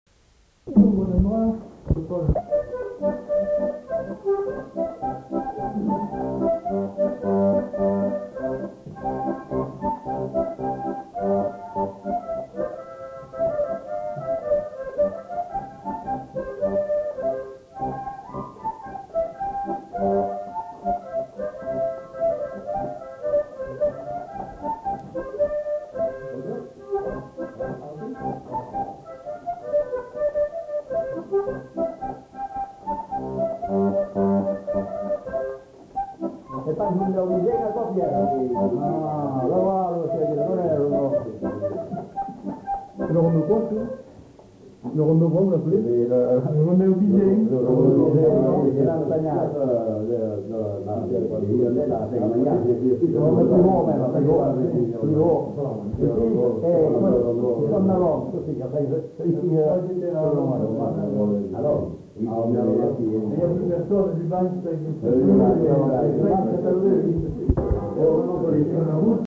Lieu : Estigarde
Genre : morceau instrumental
Instrument de musique : harmonica ; accordéon diatonique
Danse : rondeau
Notes consultables : En fin de séquence, une discussion inaudible.